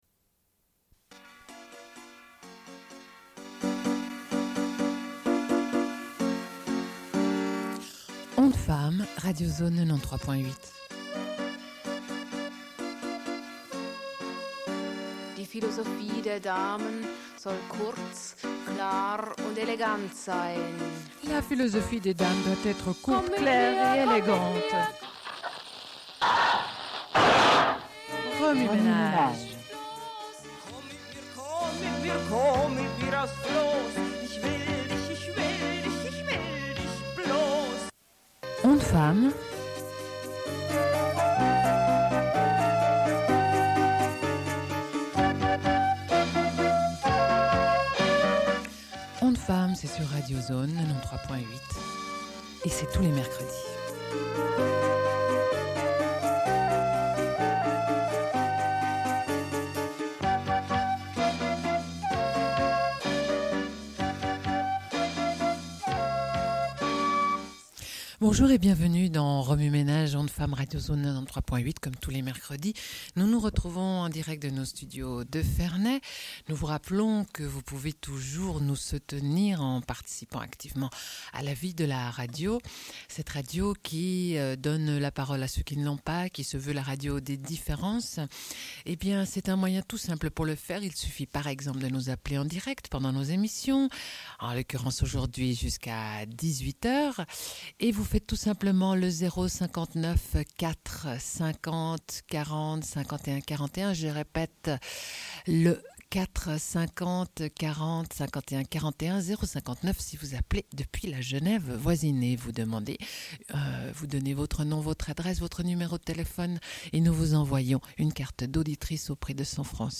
Une cassette audio, face A
Genre access points Radio Enregistrement sonore